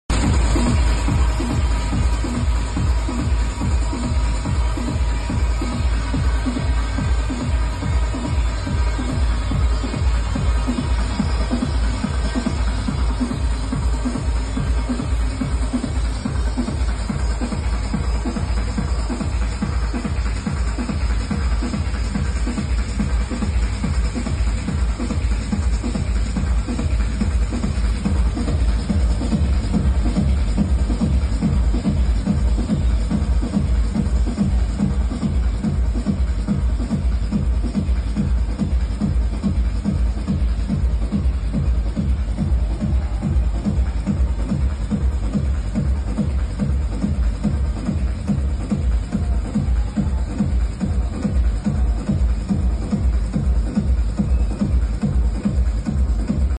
Vinyl set